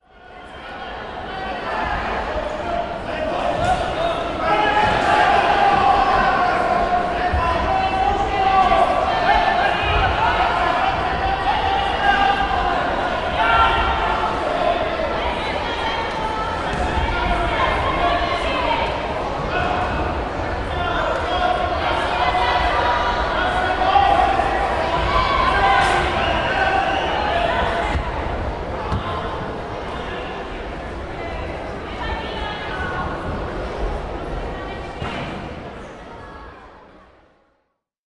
体育馆内，裁判员吹响哨子
描述：裁判员在体育馆内吹口哨
标签： 礼堂 体育馆 裁判 篮球 裁判 哨子 体育馆 裁判 教练
声道立体声